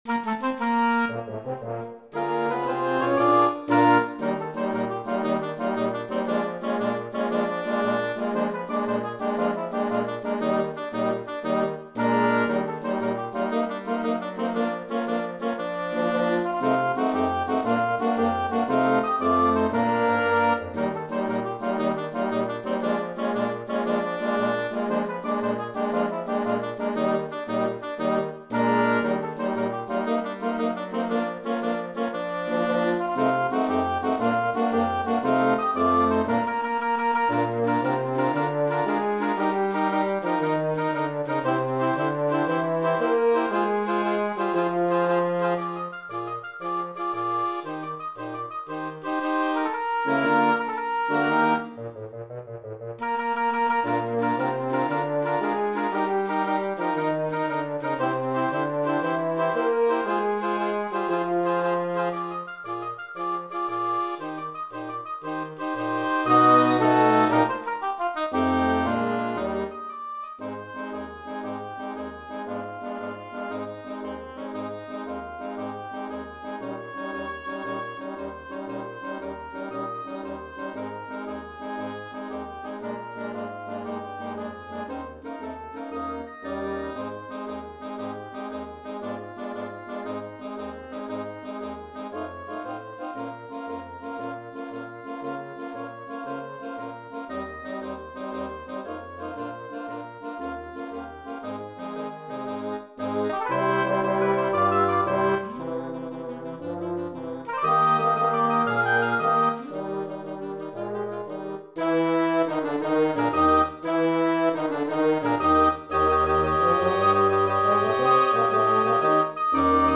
Voicing: 5 Players